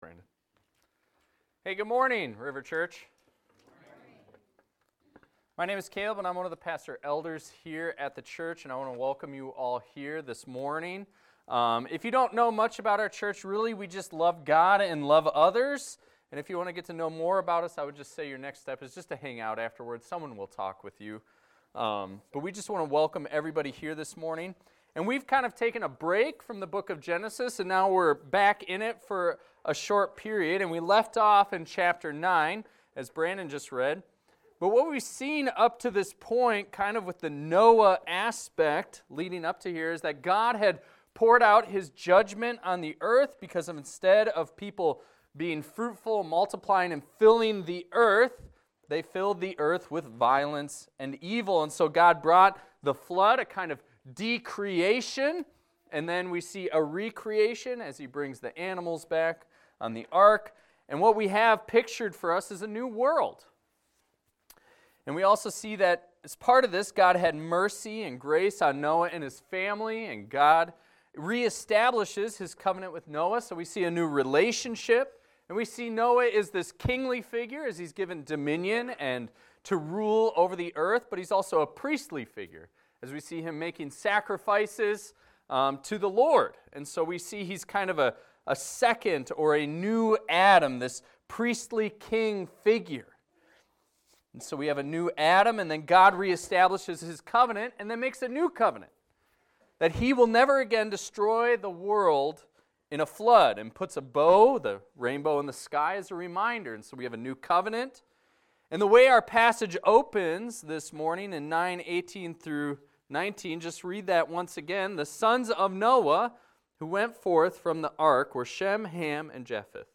This is a sermon on Genesis 9:18-29 titled "Covering the Shame" about Noah and his sons after the flood and how sin persists but how God's love persists too.